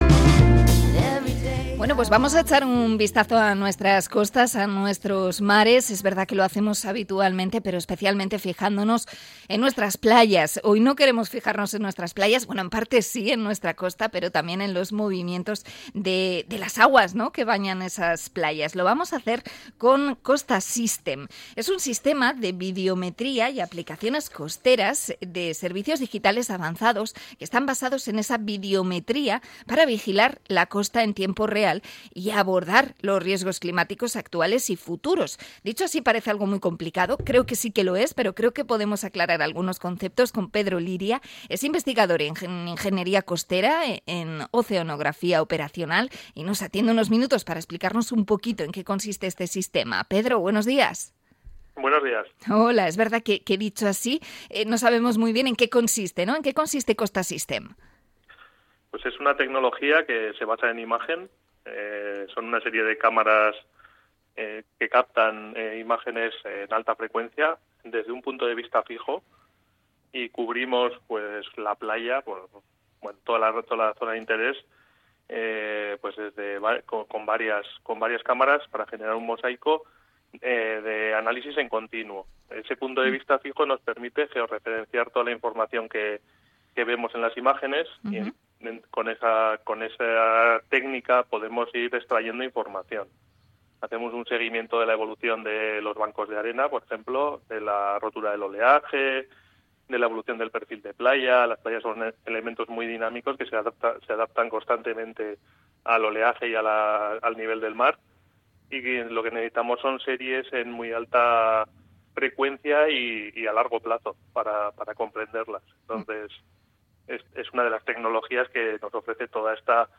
Entrevista a AZTI por el sistema de vigilancia de costas Kostasystem